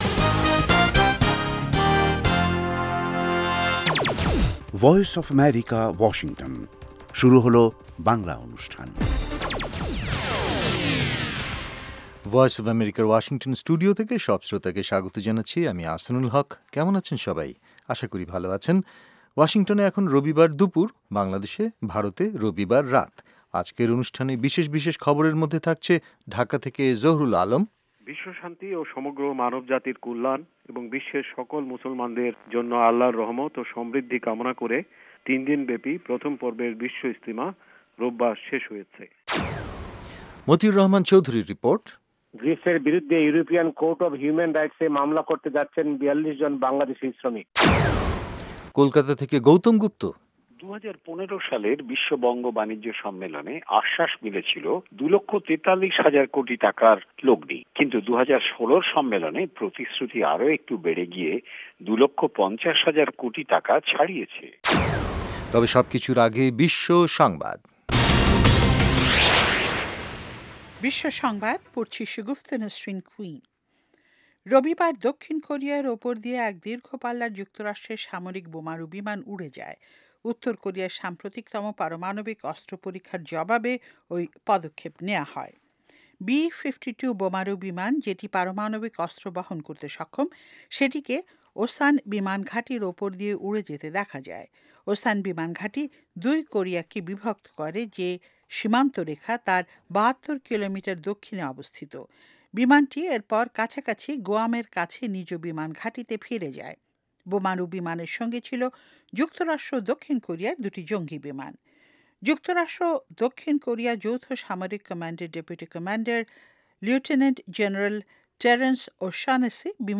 অনুষ্ঠানের শুরুতেই রয়েছে আন্তর্জাতিক খবরসহ আমাদের ঢাকা এবং কলকাতা সংবাদদাতাদের রিপোর্ট সম্বলিত বিশ্ব সংবাদ, এর পর রয়েছে ওয়ার্ল্ড উইন্ডোতে আন্তর্জাতিক প্রসংগ, বিজ্ঞান জগত, যুব সংবাদ, শ্রোতাদের চিঠি পত্রের জবাবের অনুষ্ঠান মিতালী এবং আমাদের অনুষ্ঠানের শেষ পর্বে রয়েছে যথারীতি সংক্ষিপ্ত সংস্করণে বিশ্ব সংবাদ।